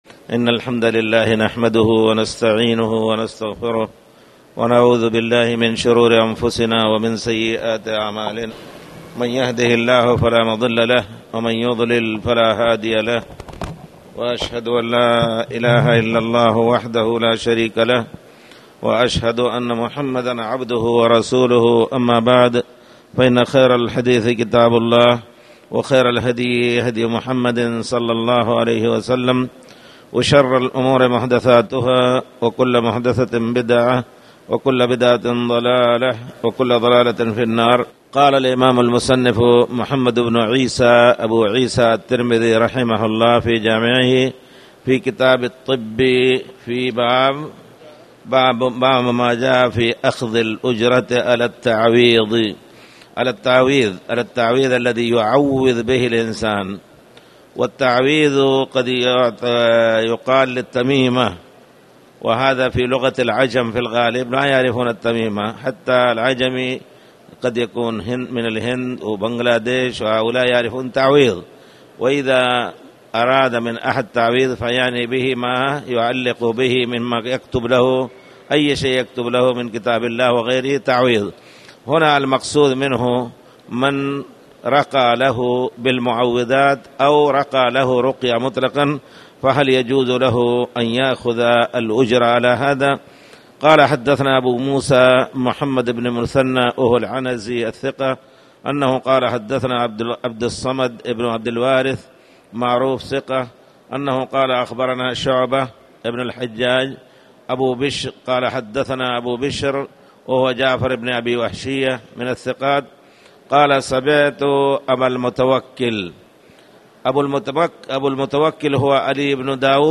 تاريخ النشر ٢٤ محرم ١٤٣٩ هـ المكان: المسجد الحرام الشيخ